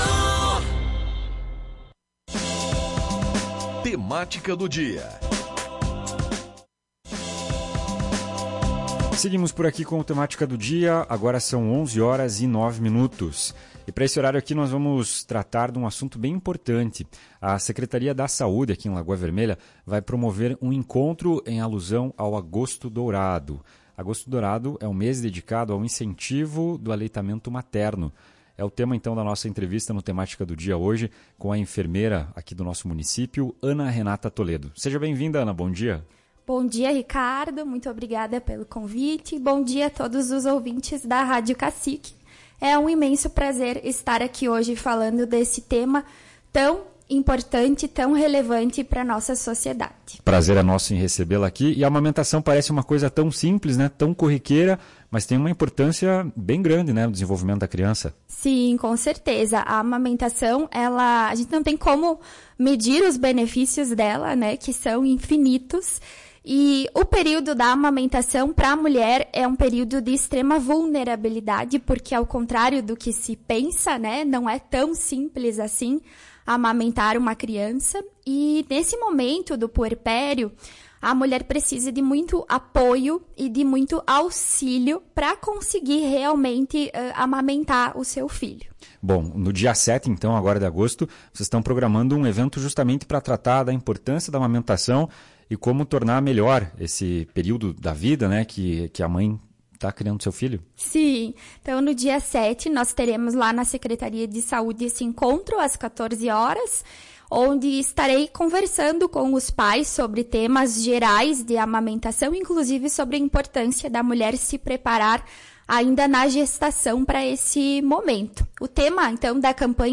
Especialista fala sobre benefícios da amamentação